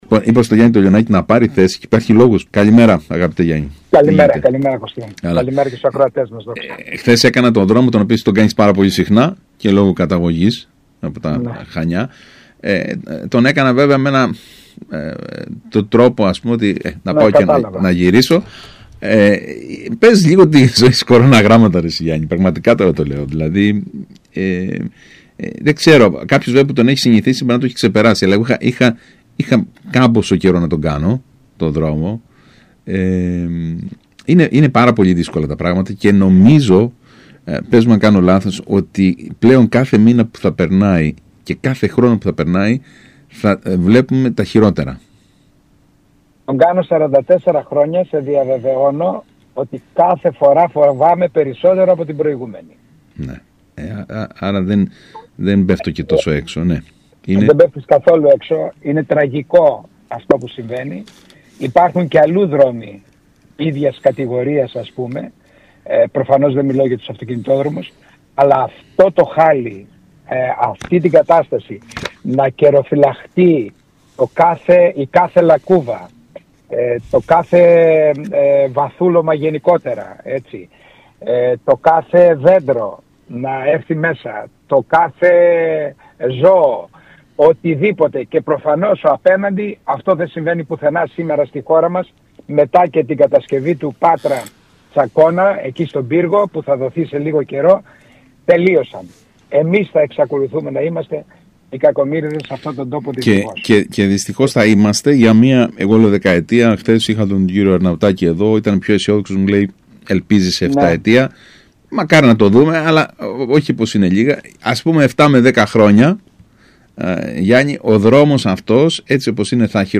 παραδέχθηκε μιλώντας στον ΣΚΑΙ Κρήτης